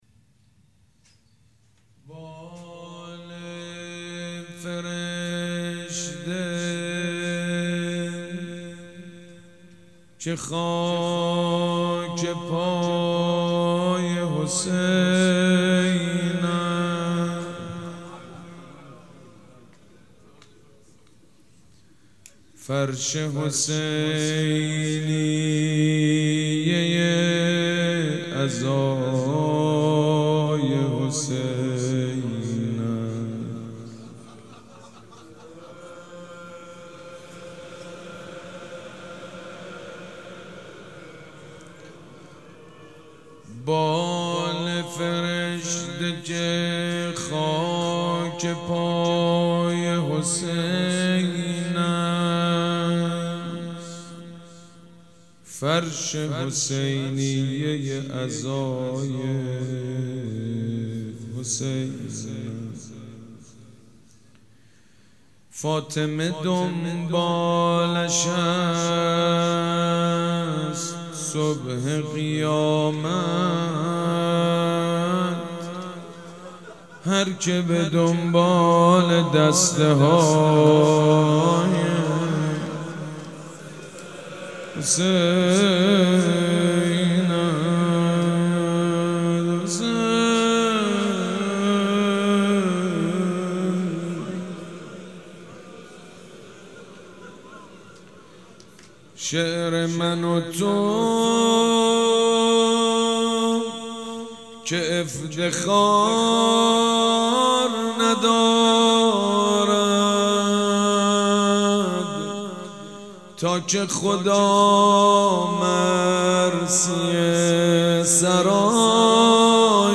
شب هشتم محرم
روضه بخش اول favorite
سبک اثــر روضه